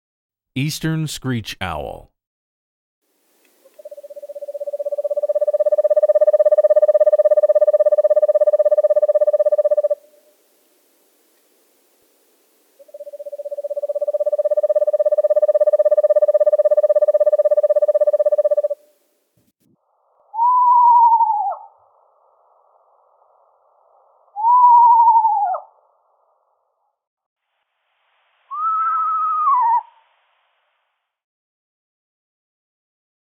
Eastern Screech Owl (Red-morph)
Eastern Screech-Owl.mp3